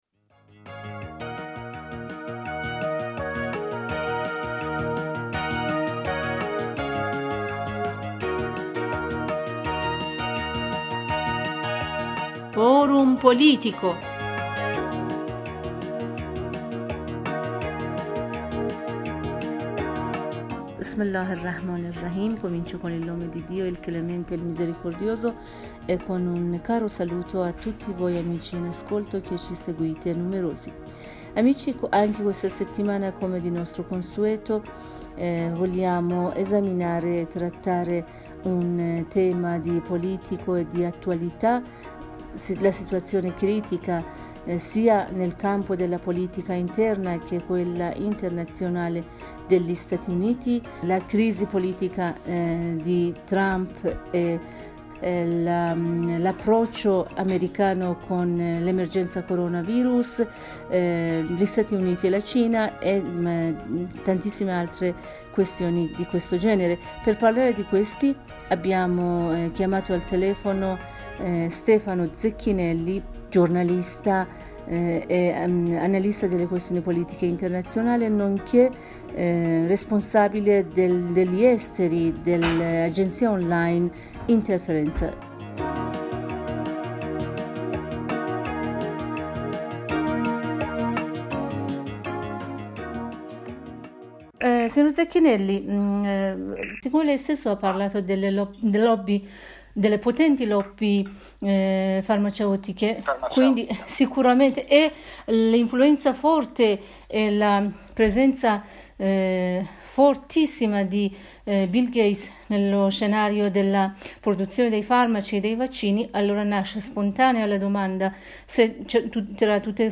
Per ascoltare la versione integrale dell'intervista cliccare qui sopra: